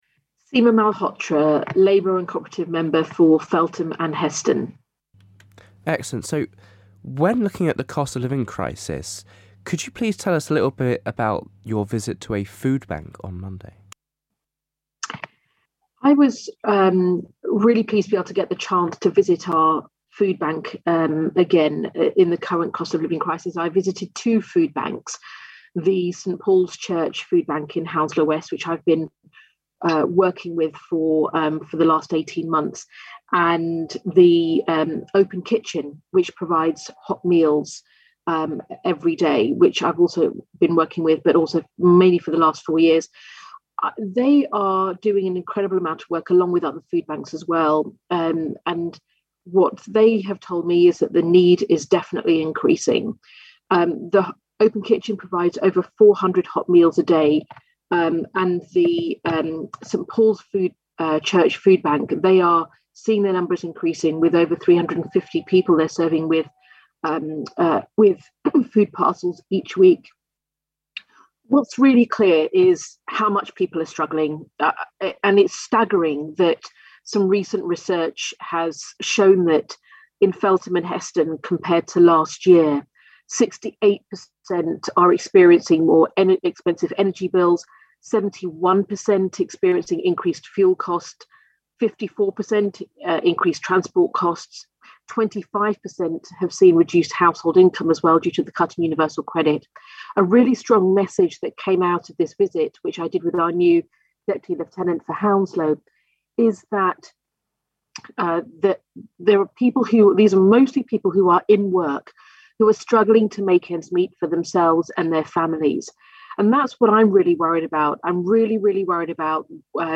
Feltham and Heston MP, Seema Malhotra gives us her monthly roundup.